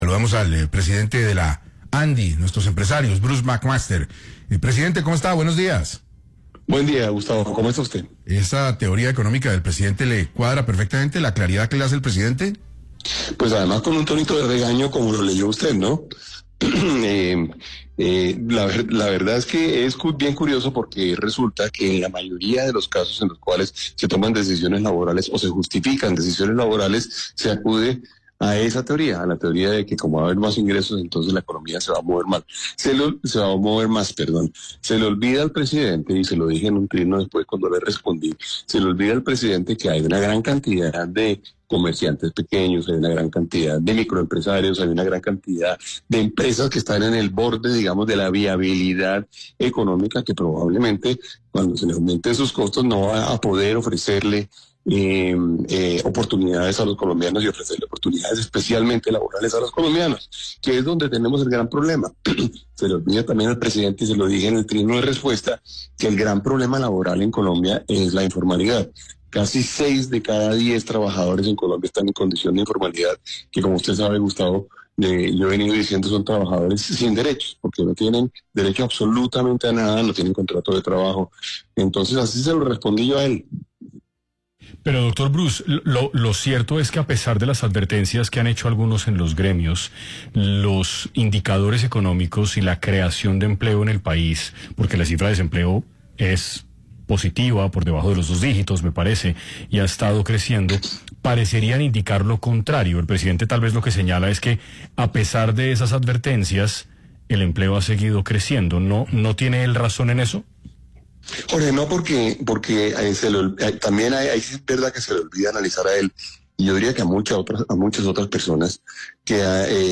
En entrevista exclusiva con 6AM de Caracol Radio, el presidente de la Asociación Nacional de Empresarios de Colombia (ANDI), Bruce Mac Master, respondió con firmeza a las insinuaciones del presidente Gustavo Petro, quien ha vinculado su nombre con un supuesto golpe de Estado y lo ha relacionado directamente con el excanciller Álvaro Leyva Durán.